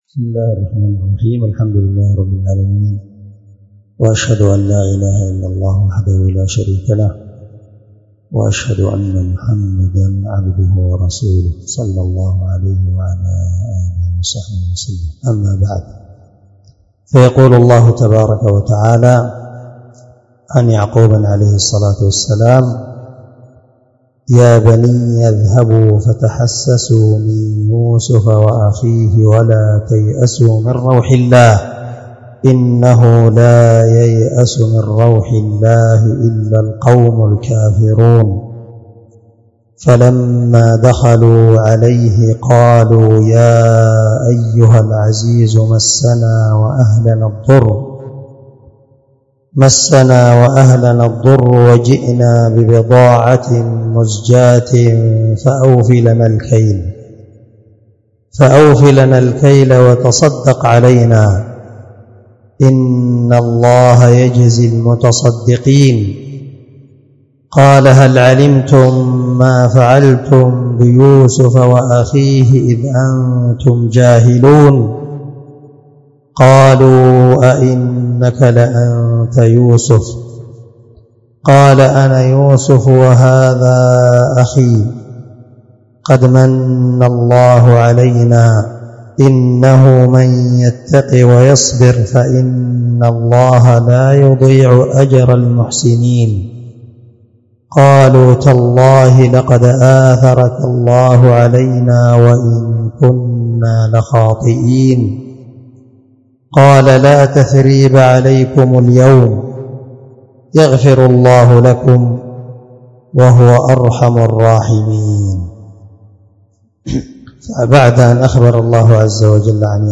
666تفسير السعدي الدرس16 آية (87_92) من سورة يوسف من تفسير القرآن الكريم مع قراءة لتفسير السعدي
دار الحديث- المَحاوِلة- الصبيحة.